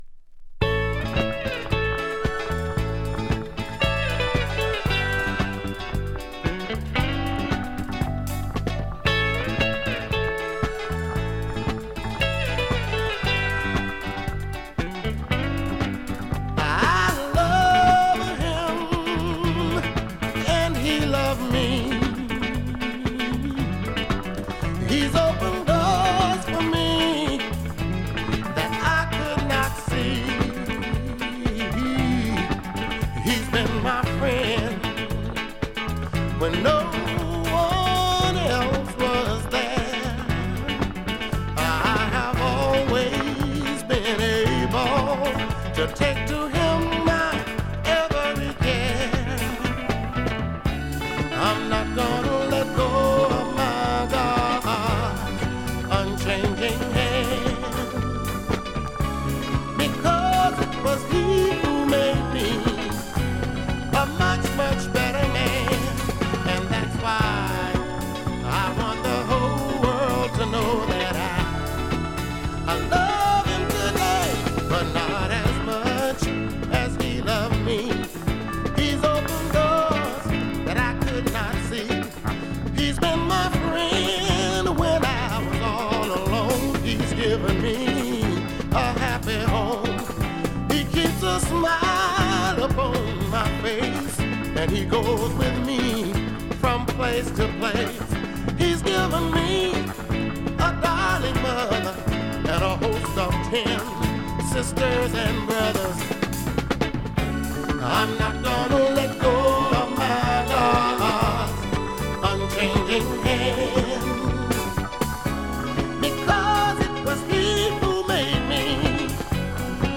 Gospel/Soul ゴスペル・ソウルシンガー